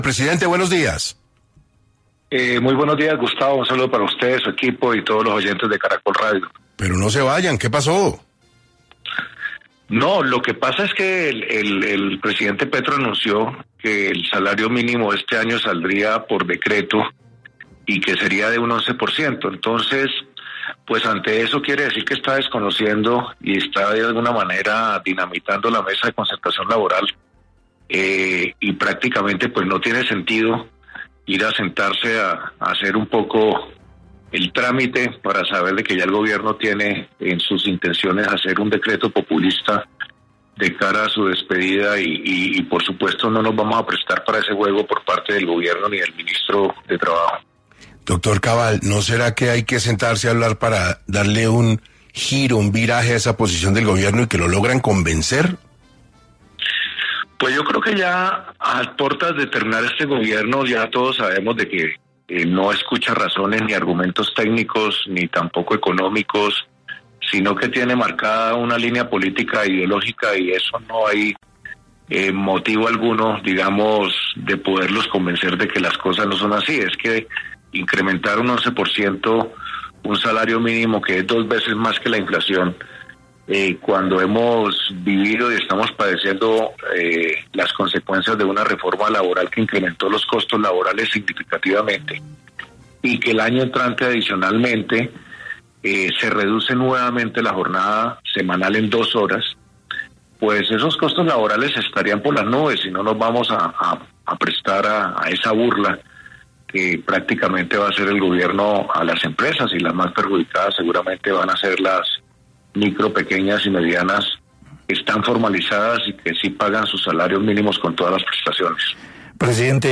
En entrevista con 6AM